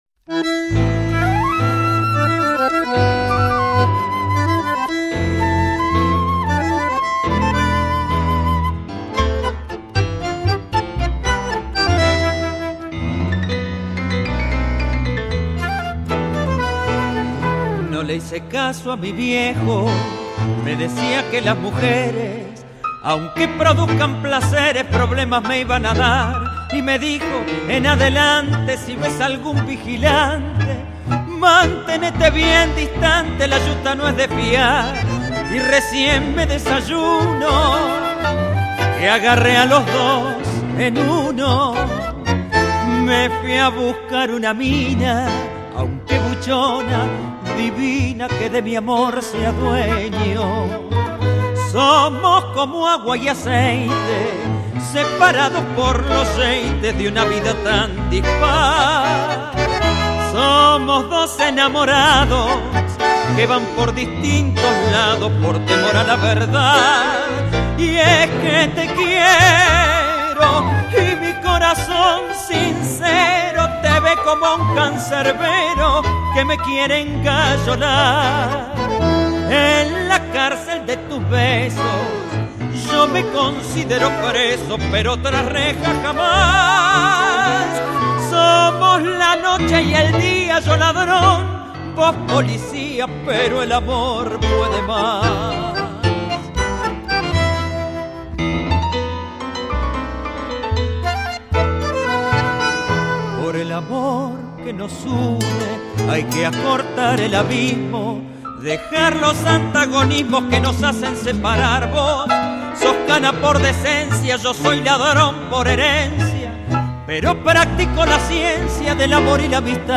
Tango